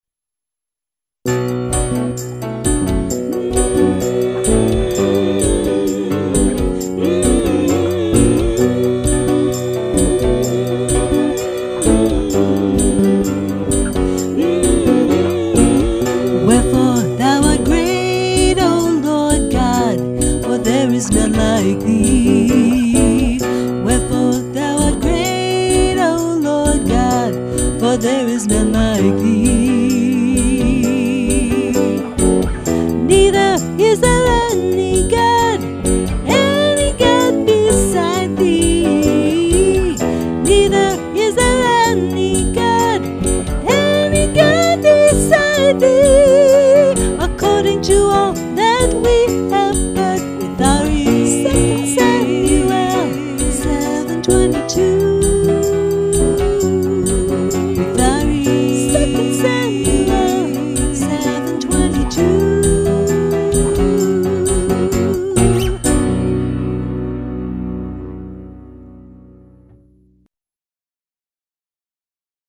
With Vocals |